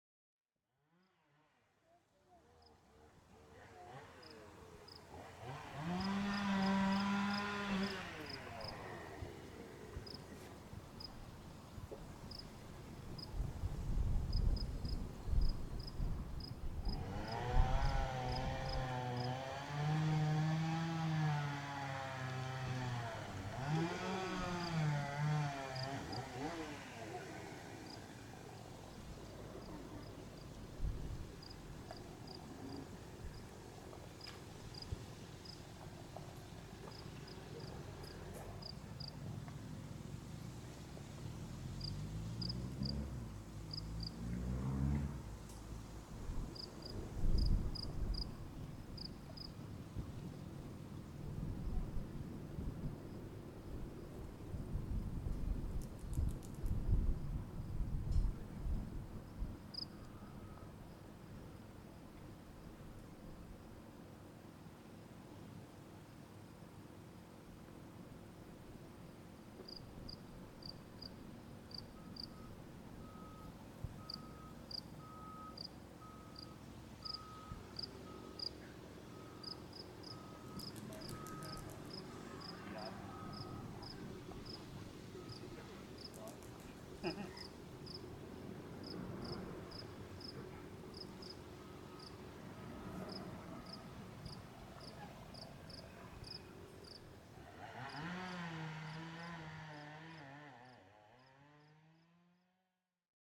セットフォード鉱山にて録音
操業をやめた静かな時間が流れていた。
Thetford-Mine_mixdown.mp3